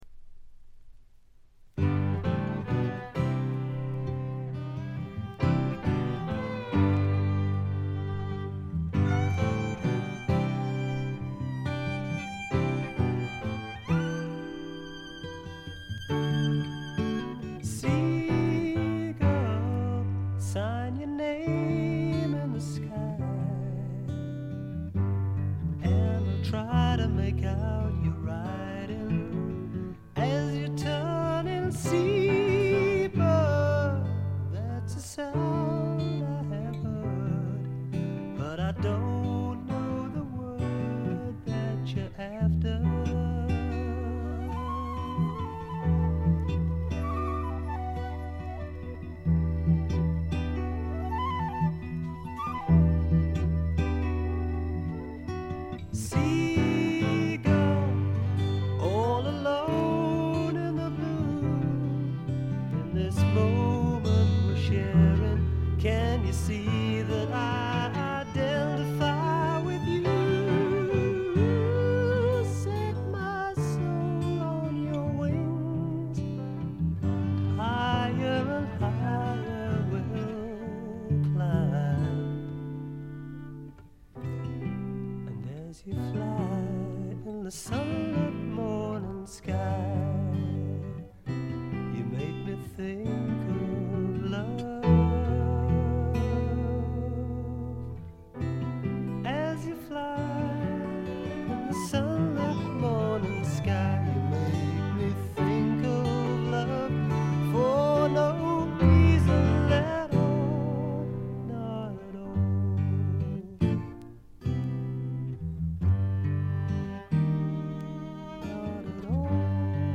ほとんどノイズ感無し。
内容は枯れた感じのフォーク・ロック基調でちょっと英国スワンプ的な雰囲気もあり、実に味わい深いアルバムとなりました。
試聴曲は現品からの取り込み音源です。
Guitar, Piano, Violin, Vocals
Drums, Percussion